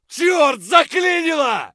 gun_jam_2.ogg